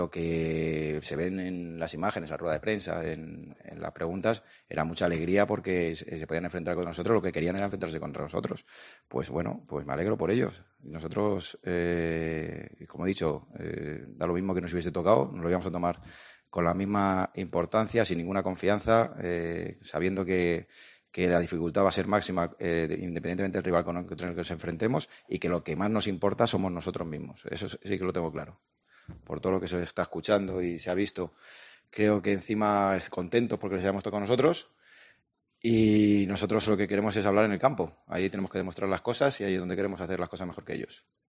Me alegro por ellos de que estén contentos. Gustarme no me gusta. Cualquiera puede mostrar su opinión, creo que están en su derecho de estar contentos y alegrarse y esto nos motiva todavía más”, dijo Calleja en la rueda de prensa previa al choque de ida de este sábado en el Carlos Belmonte.